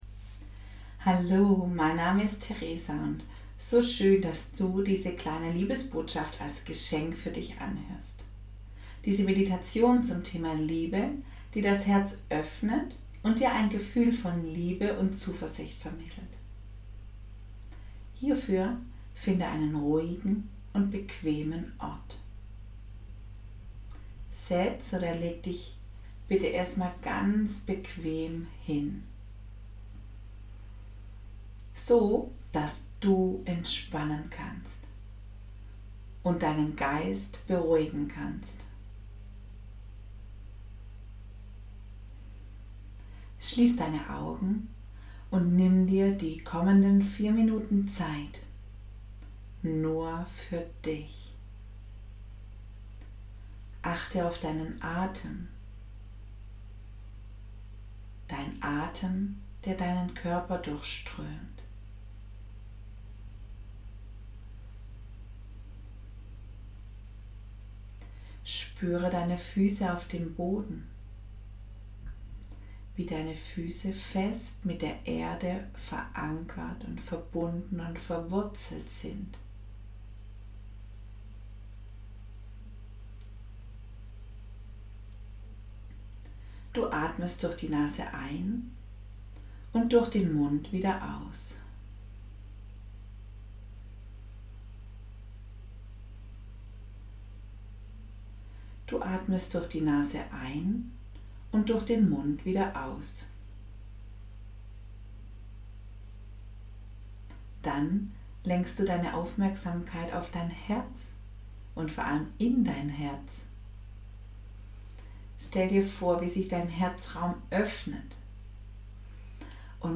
kurze Meditation.